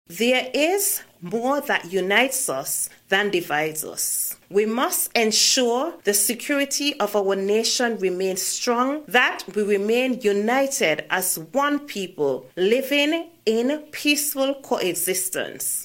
On the occasion of the nation’s 40th Independence Celebration, the Prime Minister and Premier as well as political figures in the federation addressed citizens and residents on September 19th.
Also airing her views was Member of the Opposition in the Nevis Island Assembly and Parliamentary Representative of Nevis #5 (St. Thomas’ Parish), Hon. Cleone Stapleton-Simmonds: